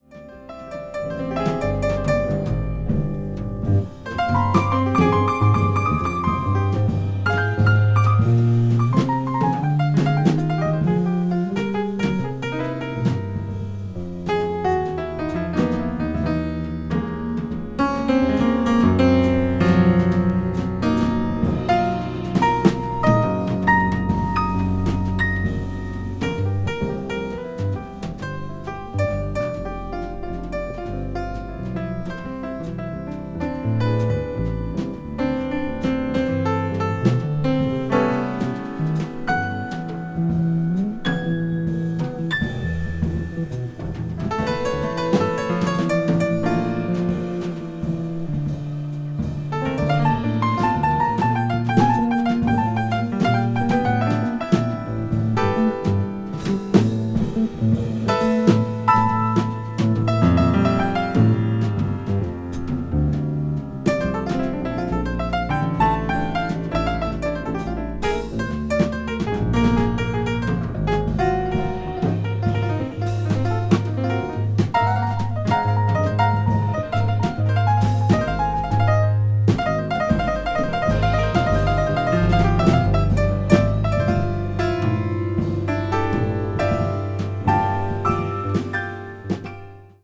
media : EX+/EX+(わずかにチリノイズが入る箇所あり)
contemporary jazz   post bop